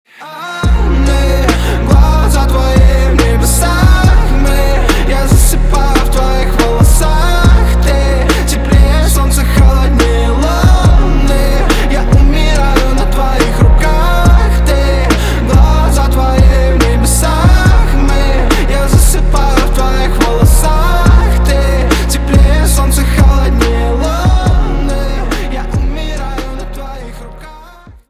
• Качество: 320, Stereo
атмосферные
медленные